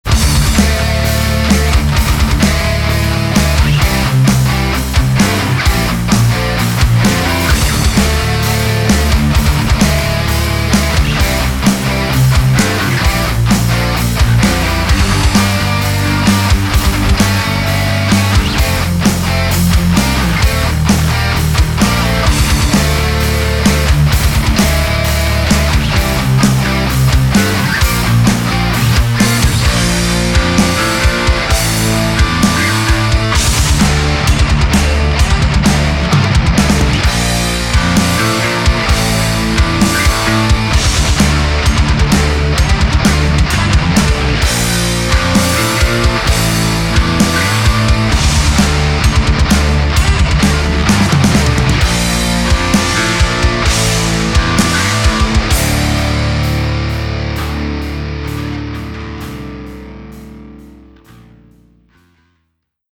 Hard'n'Heavy (NI Studio Drummer; TSE x50; TSE B.O.D.)